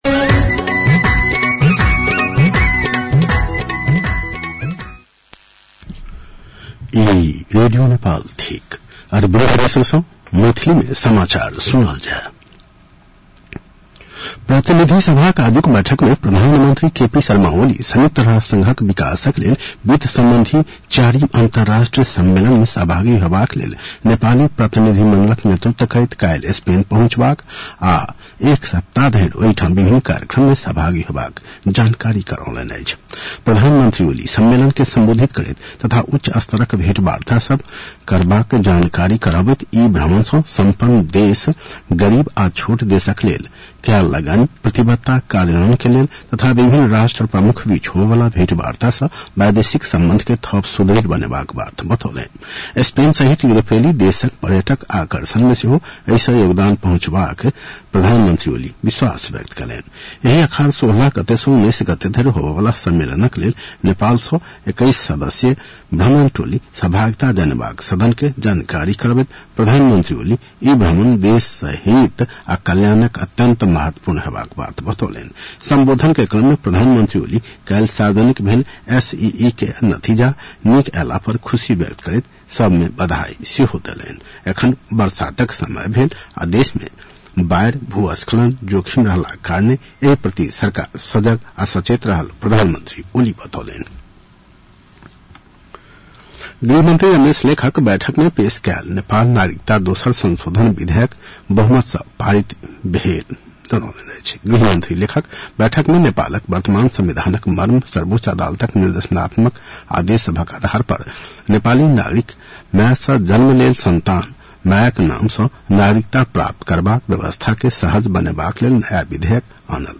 मैथिली भाषामा समाचार : १४ असार , २०८२
6-pm-maithali-news-1-2.mp3